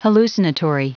Prononciation du mot hallucinatory en anglais (fichier audio)
Prononciation du mot : hallucinatory